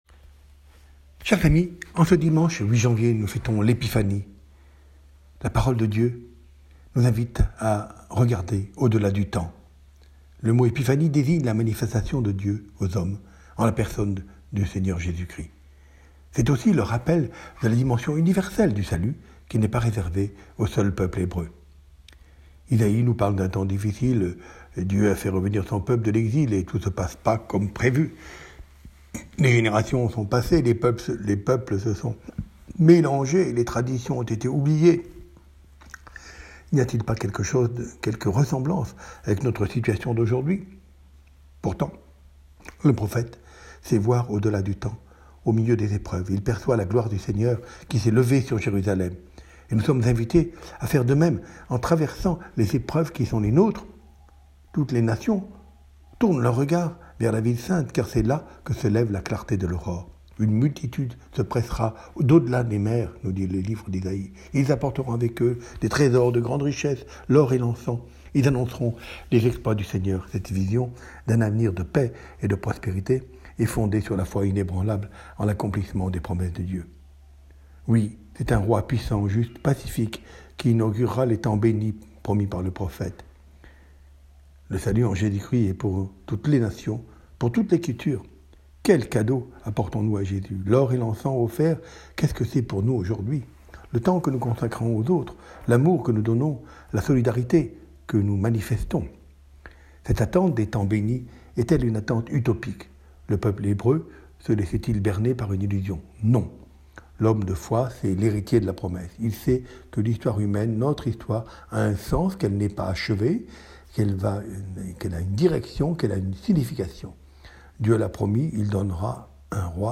Homélie de Mgr Colomb